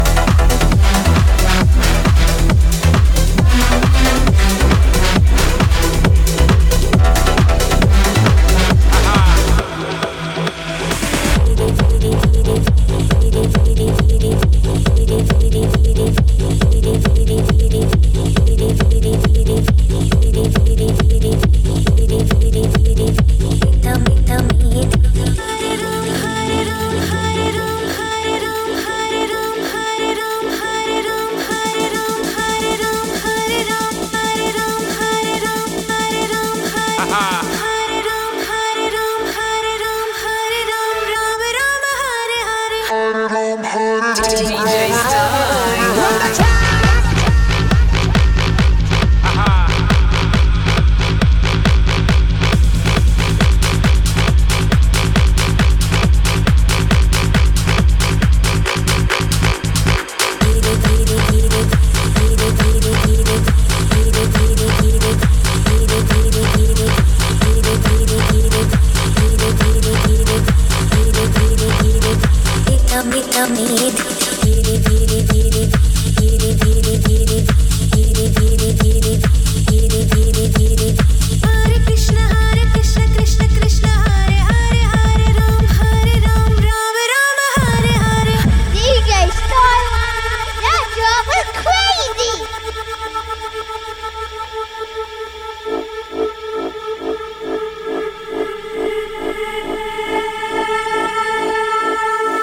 Genre: House, Electronic, Dance.